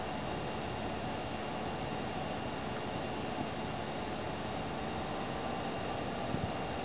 ファンノイズ比較